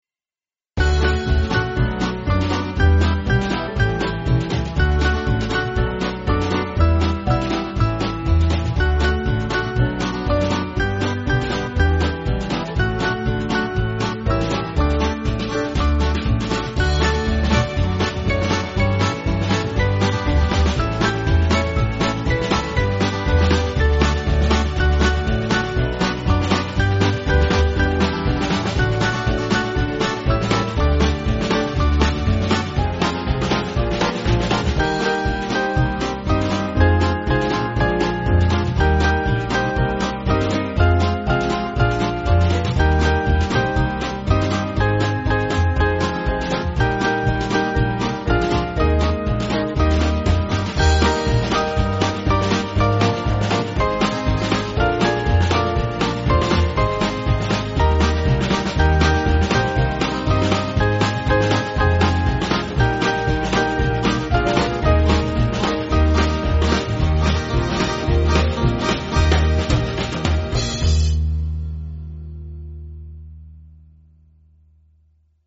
Small Band
(CM)   2/D-Eb